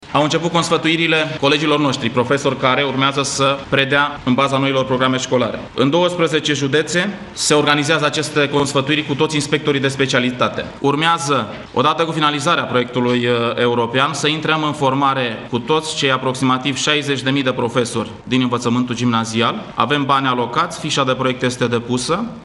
Nici profesorii nu sunt încă pregătiți să predea după noua programă, dar vor începe, în curând, cursurile de formare – a spus Liviu Pop.